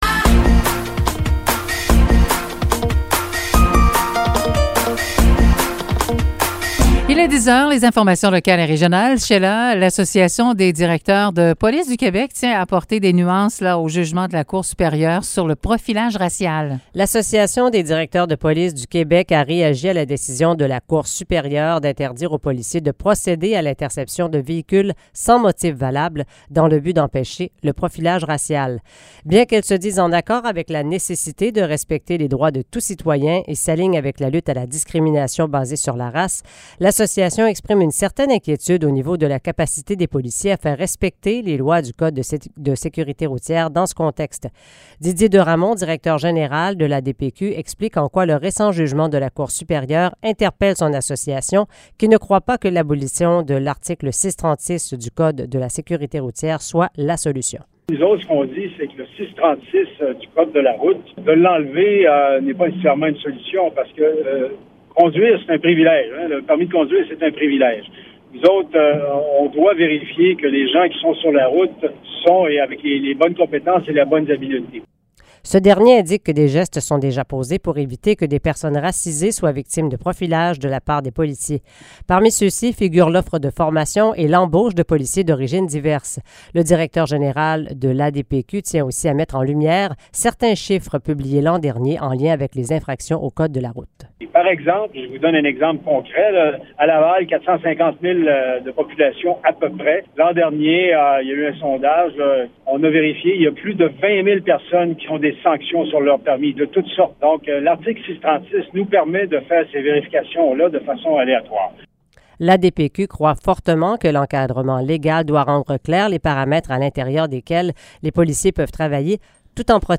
Nouvelles locales - 28 octobre 2022 - 10 h